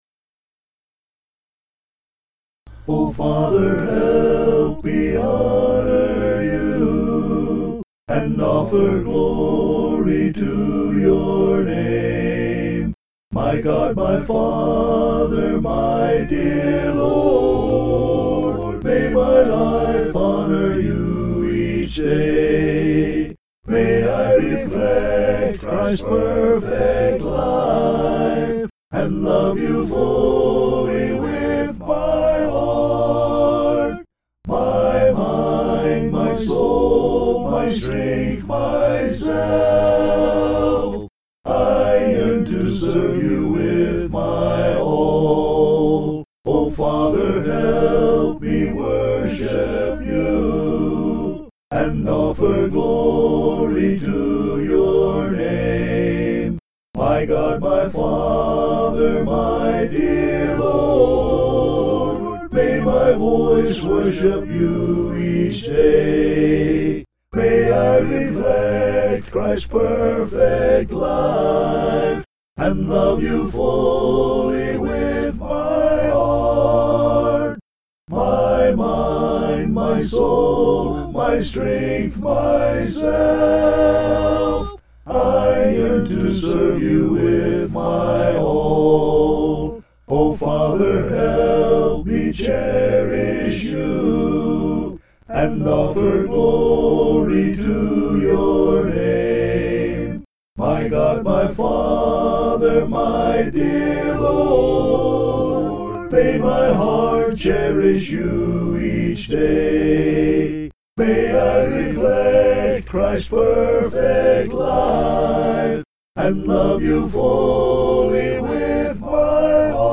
(An original hymn)